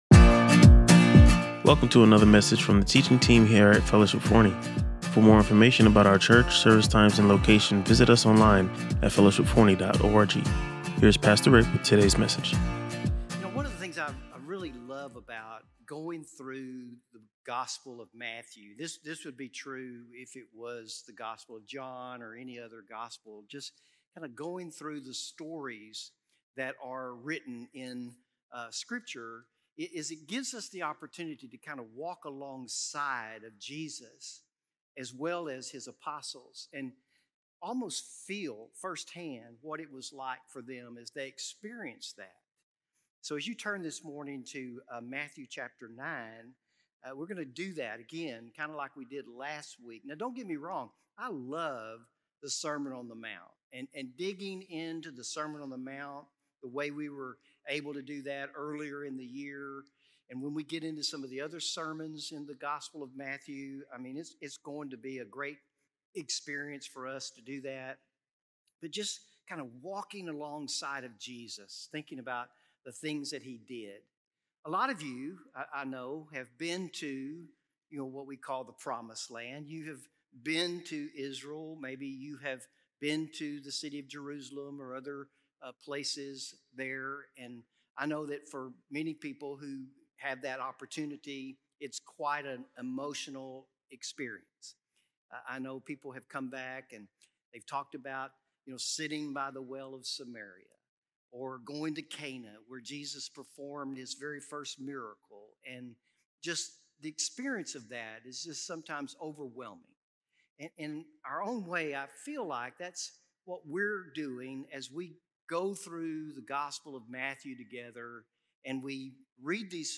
Listen to or watch the full sermon and be encouraged by this powerful message about joining Jesus in His harvest work.